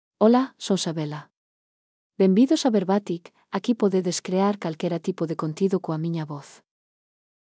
Sabela is a female AI voice for Galician.
Voice sample
Listen to Sabela's female Galician voice.
Female
Sabela delivers clear pronunciation with authentic Galician intonation, making your content sound professionally produced.